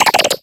a1f024acfcd88d03caa399d76e20adbc8330cf31 infinitefusion-e18 / Audio / SE / Cries / CLAUNCHER.ogg infinitefusion d3662c3f10 update to latest 6.0 release 2023-11-12 21:45:07 -05:00 8.0 KiB Raw History